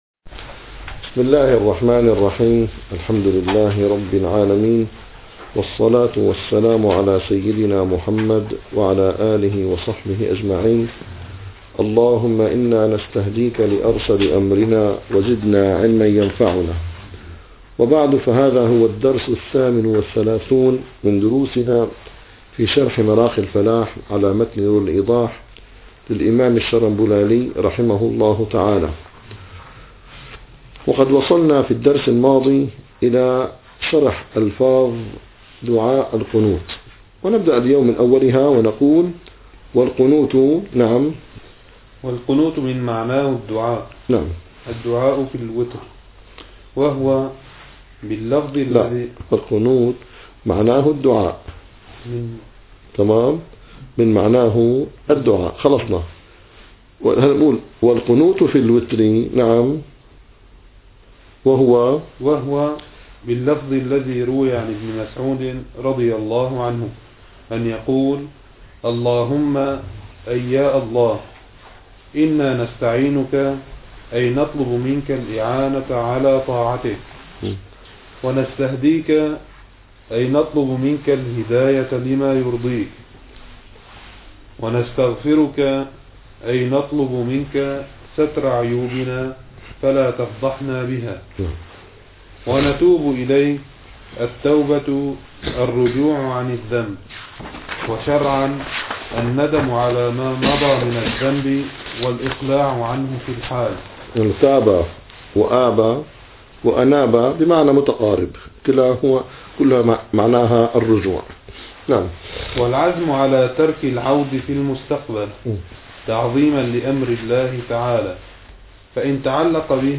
- الدروس العلمية - الفقه الحنفي - مراقي الفلاح - 38- شرح ألفاظ دعاء القنوت